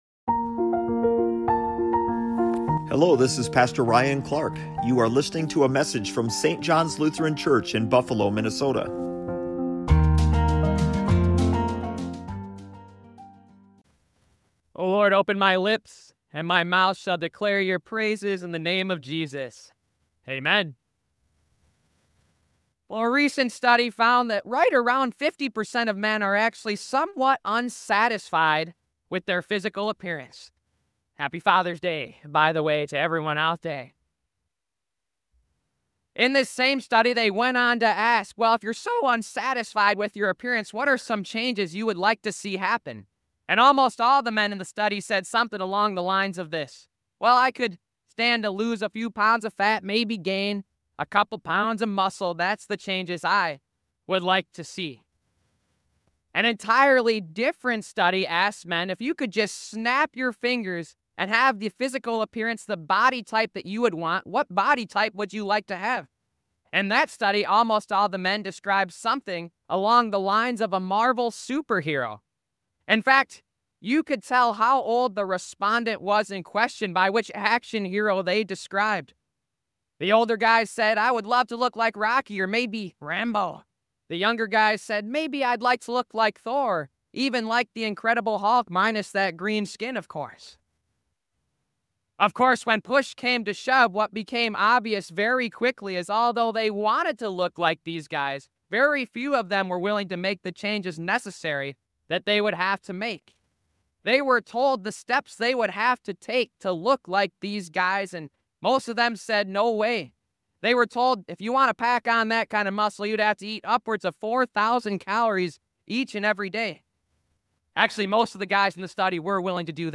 SERMONS In week 2⃣ of the Made for CommUNITY sermon series